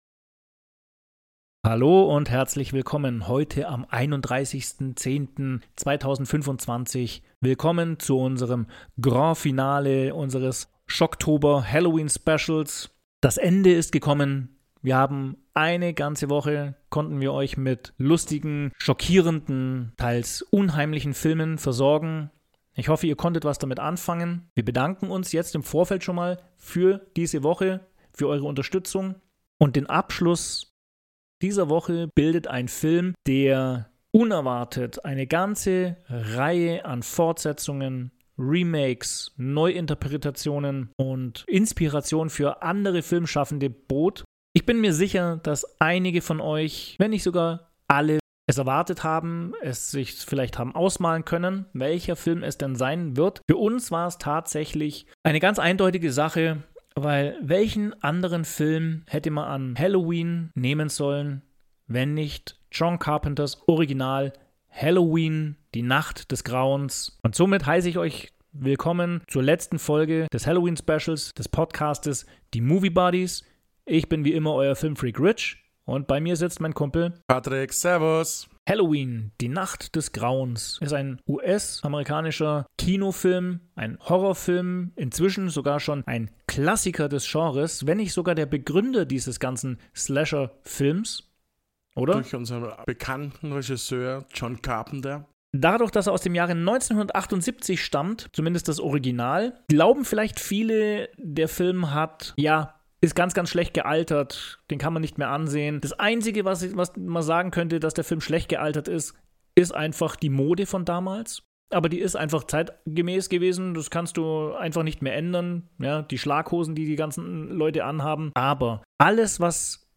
Nehmt Teil an einer spannenden Unterhaltung über die Entstehungs- und Zensurgeschichte. Über die Weiterentwicklung des Michael Myers und seine Fortsetzungen.